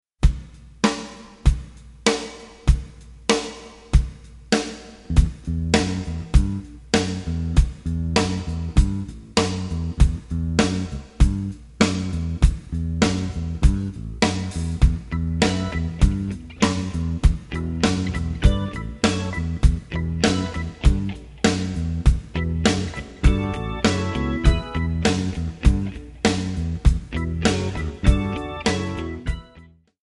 MPEG 1 Layer 3 (Stereo)
Backing track Karaoke
Pop, Rock, 1980s